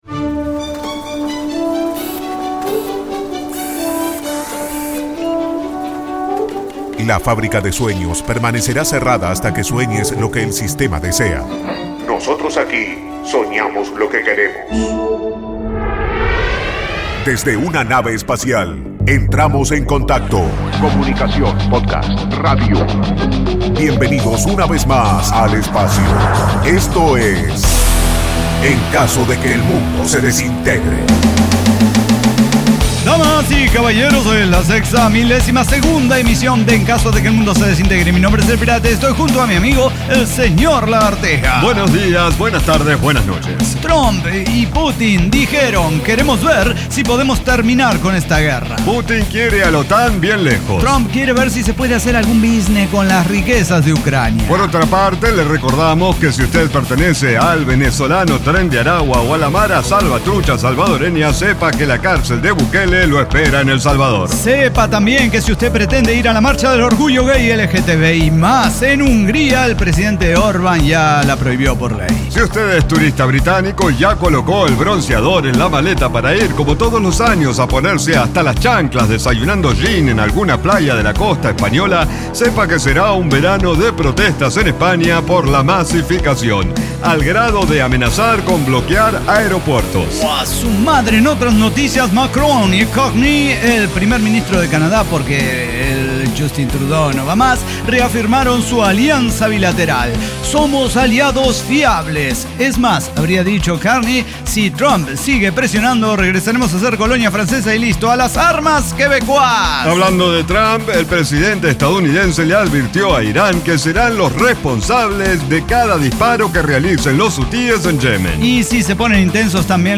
Diseño, guionado, música, edición y voces son de nuestra completa intervención humana.